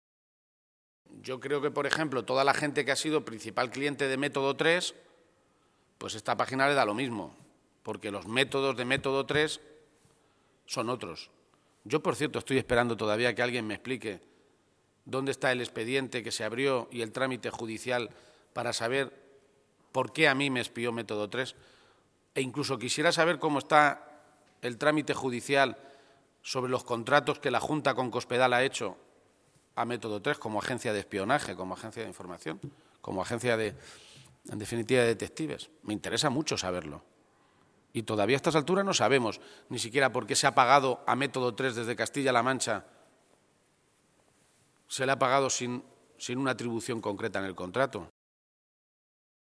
García-Page se pronunciaba de esta manera esta mañana, en Toledo, a preguntas de los medios de comunicación, durante la presentación de la web de Transparencia del Ayuntamiento de Toledo.
Cortes de audio de la rueda de prensa